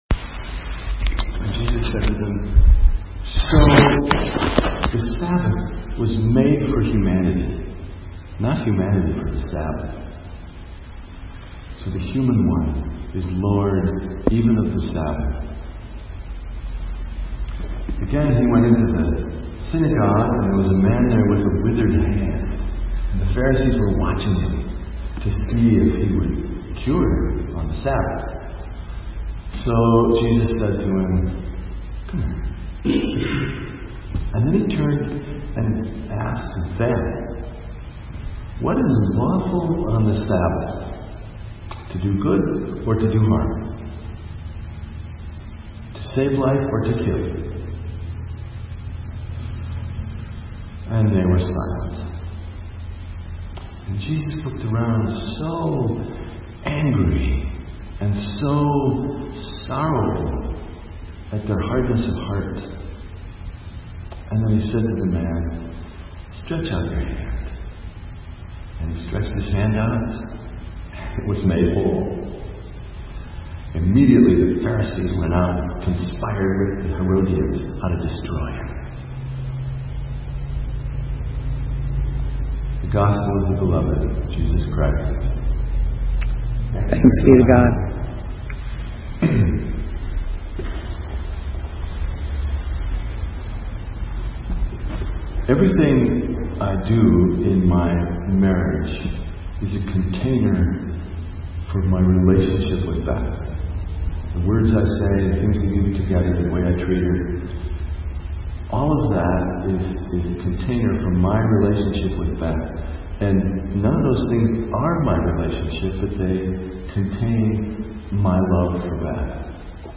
Sermon:The light, not the lamp - St. Matthews United Methodist Church